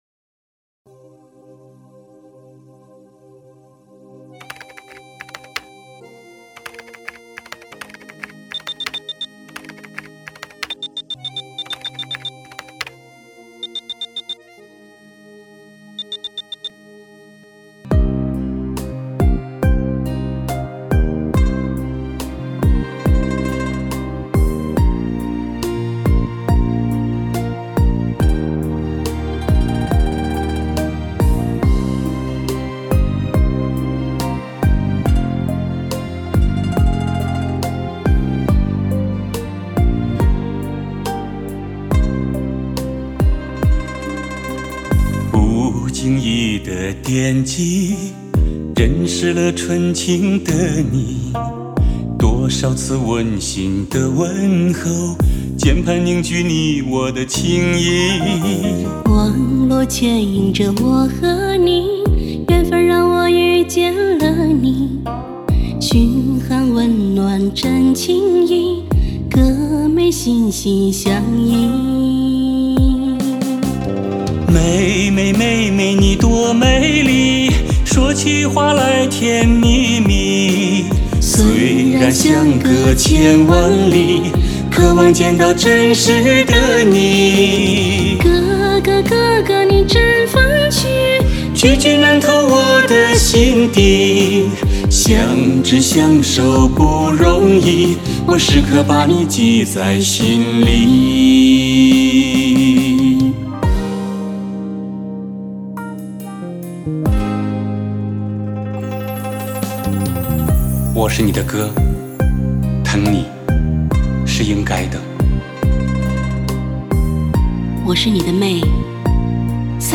主打歌曲